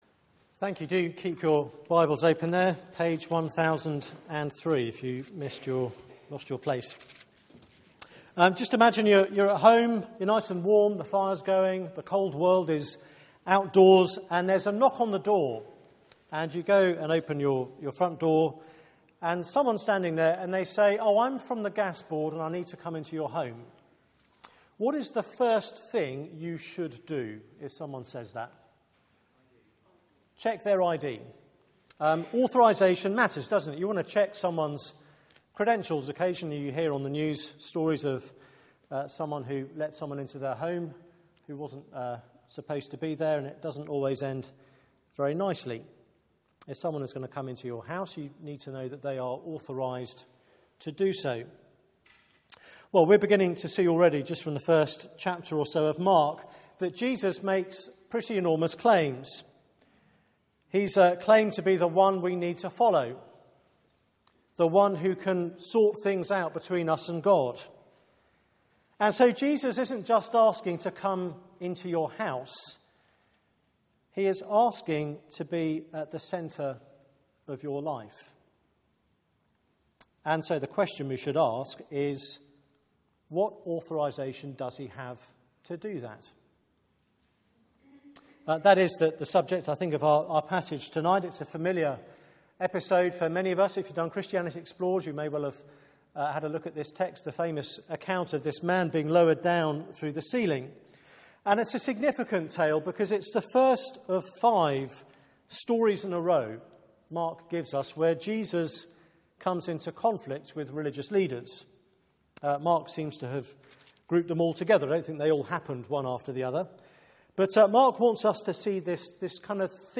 Bible Text: Mark 2:1-12 | Preacher